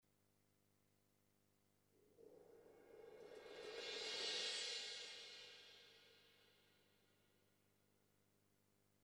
In total this loop song contains 33 loops. 13 of them are pure playing on the cymbals. The snare and toms has a lot of reverb because i wanted this loops song To be best for romantic style that will be great with strings and nice chorus guitars.
This 80 bpm 6/8 signature is basically waltz rhythm but in this days
This style of rhythm is for slow rock songs and romantic songs.